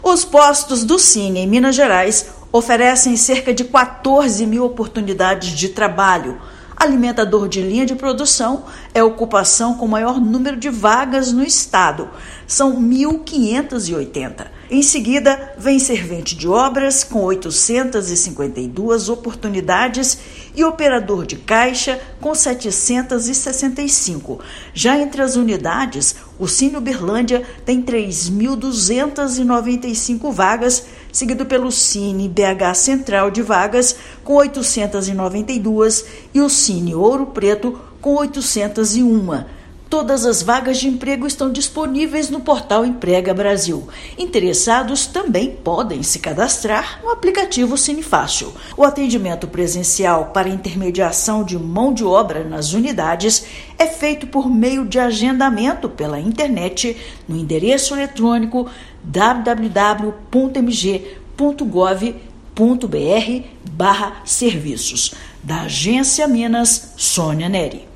Alimentador de linha de produção é a função com maior número de vagas. Ouça matéria de rádio.